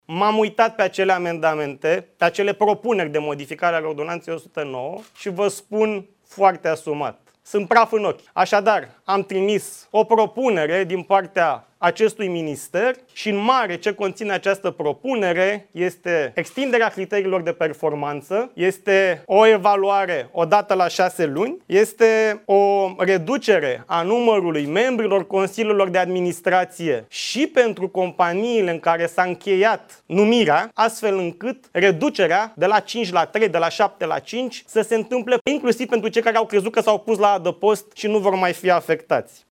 Ministrul Economiei, Radu Miruță: „Vă spun foarte asumat: sunt praf în ochi”